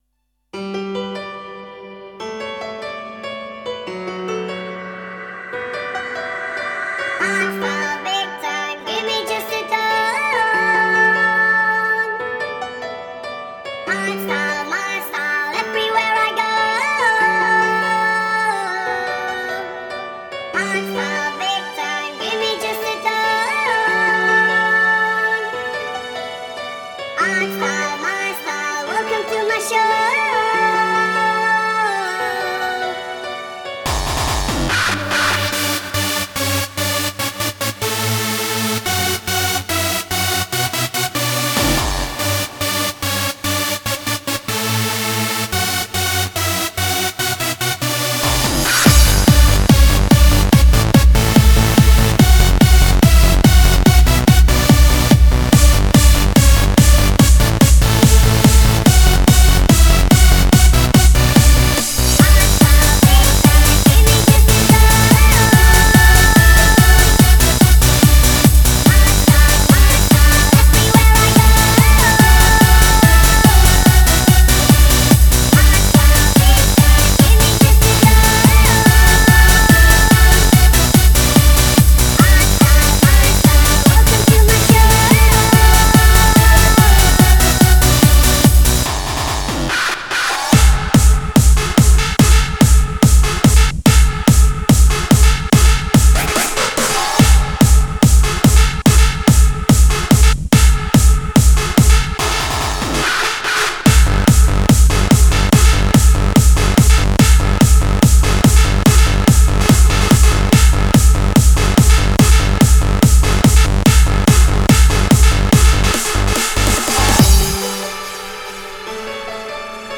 Категория: Клубняк